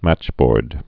(măchbôrd)